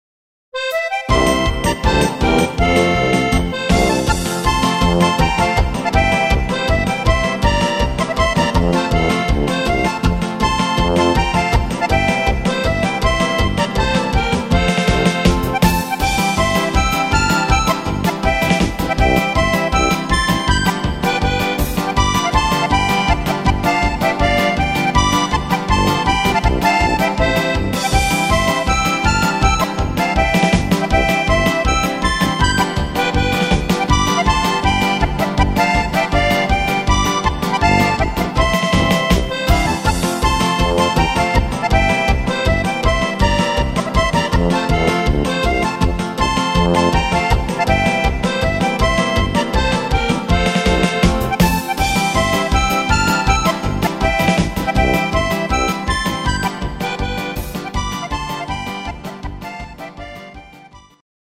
instr. steirische Harmonika